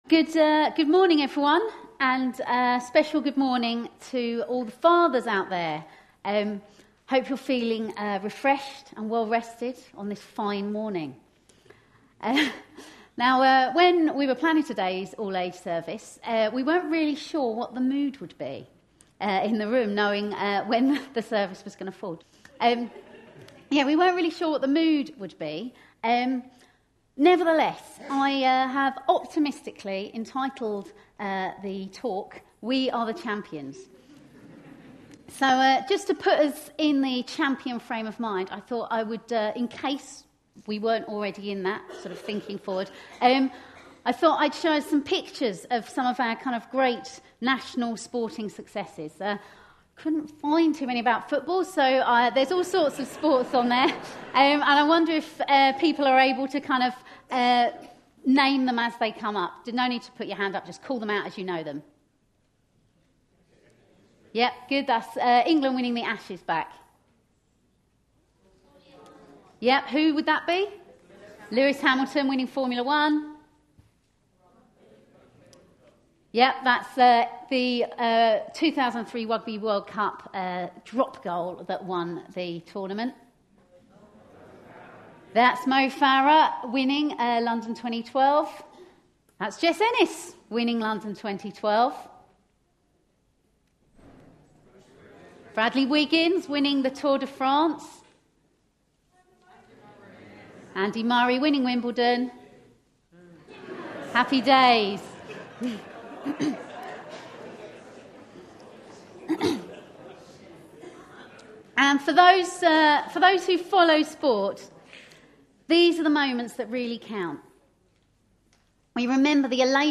A sermon preached on 15th June, 2014.
Luke 9:25 Listen online Details No reading, but talk based on Luke 9:25 with a reference to 1 Corinthians 9:24b-25. The theme of this all-age service was Father's Day and the 2014 football World Cup.